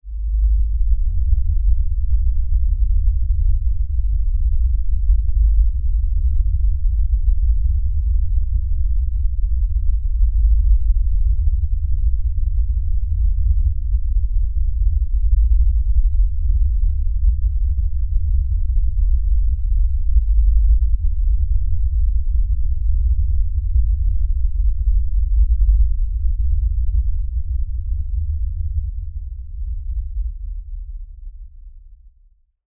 Rumble.mp3